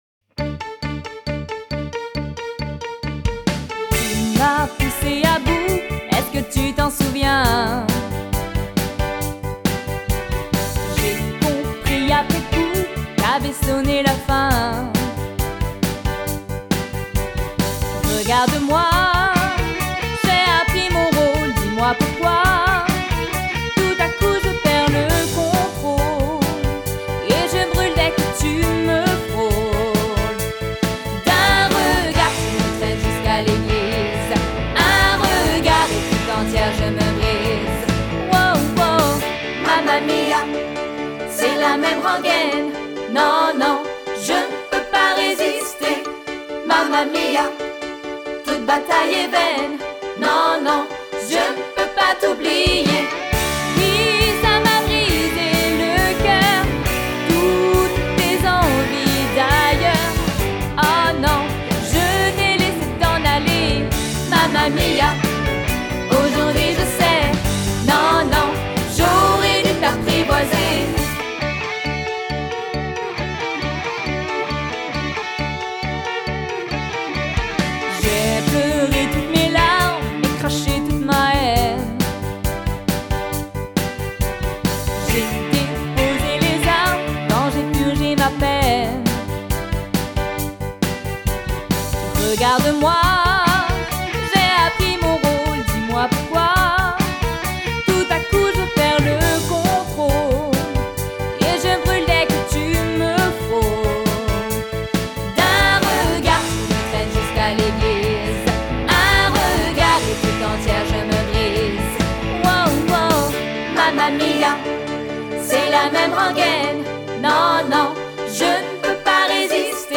20 - 34 ans - Soprano